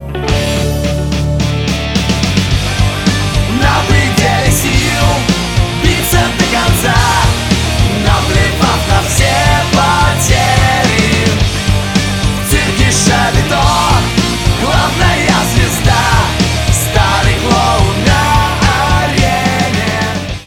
• Качество: 192, Stereo
рок